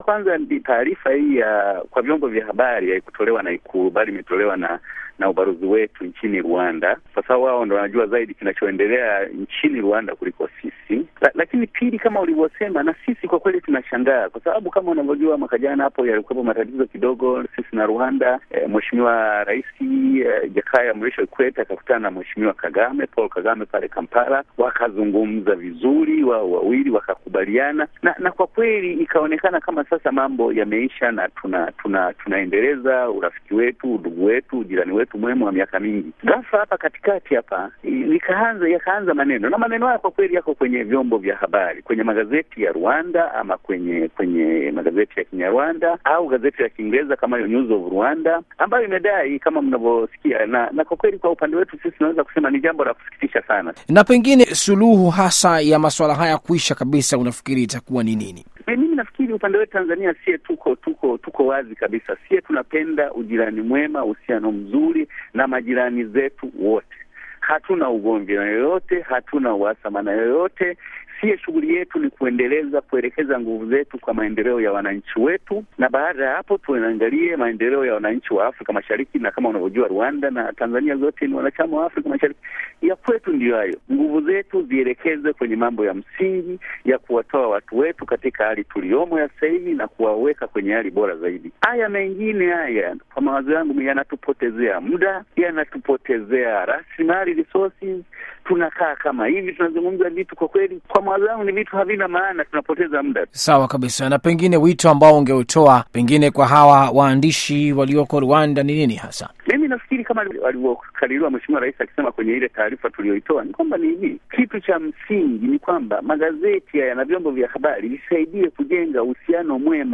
Mahojiano na Salva Rweyemamu